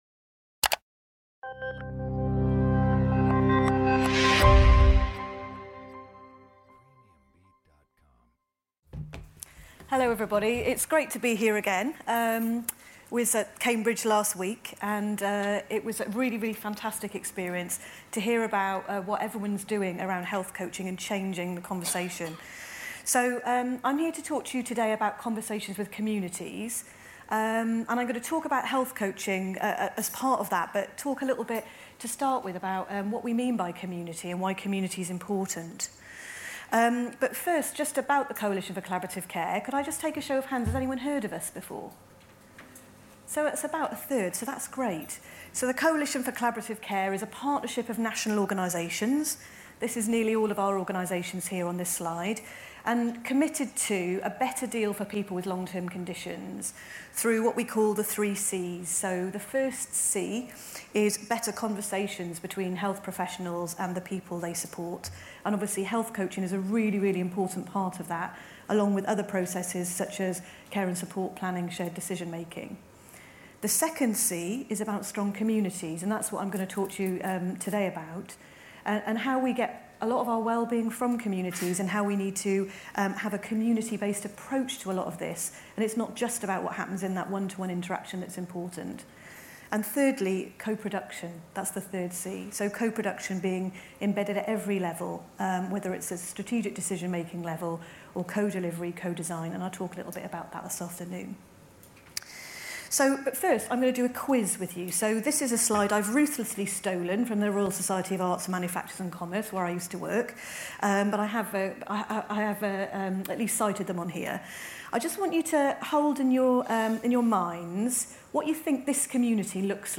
Recorded Live in Liverpool at Radisson Blu on 26th Sept 2016